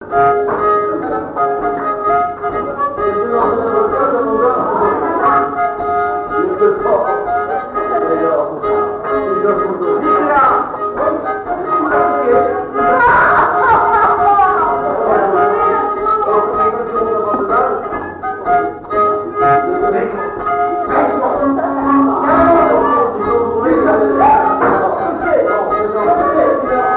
danse : marche
Pièce musicale inédite